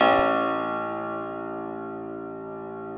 53k-pno01-A-1.wav